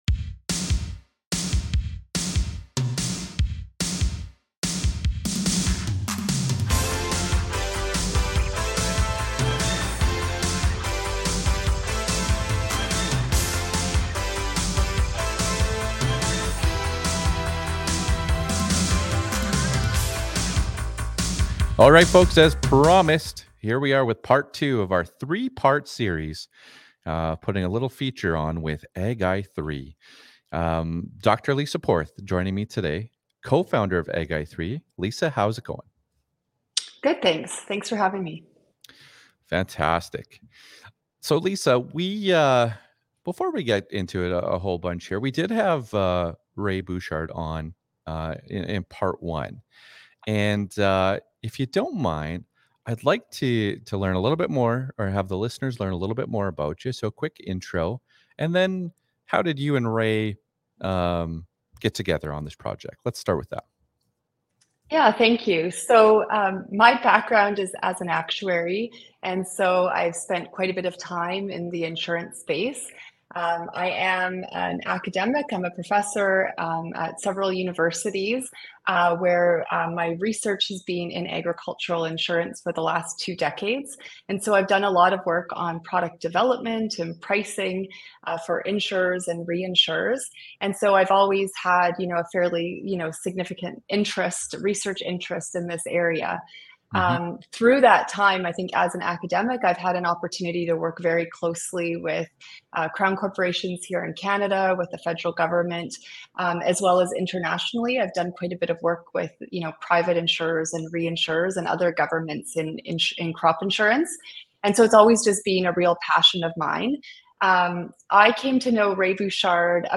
in this 3 part interview series that takes a deep dive into Prairie based crop insurance. Agi3 is a yield-based crop insurance, tailored to fit your unique farm.